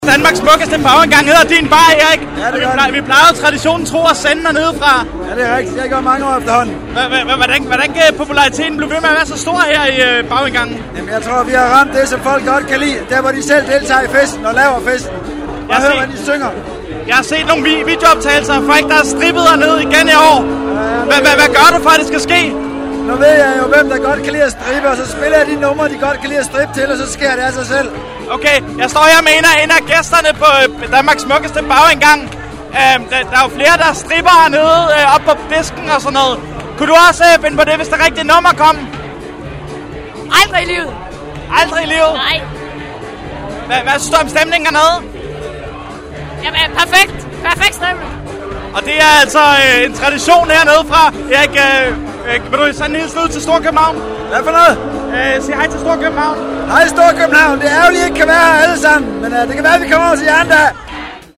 Kategori: Interviews